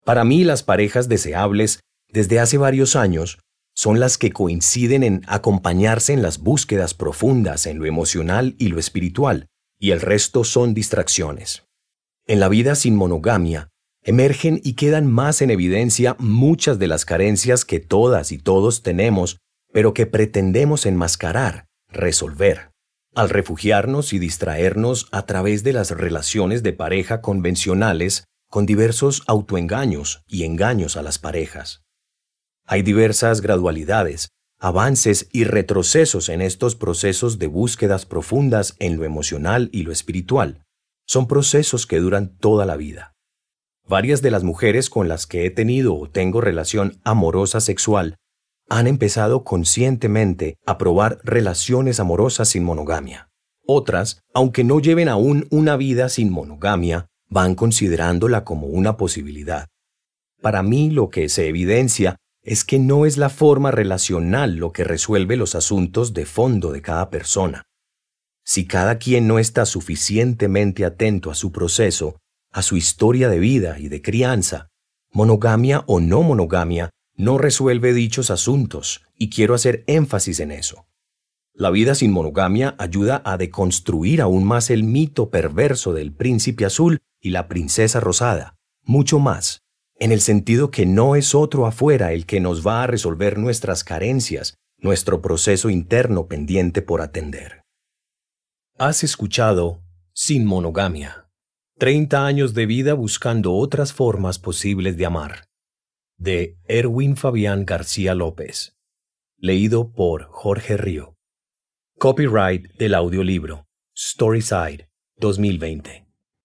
Fragmentos de audiolibros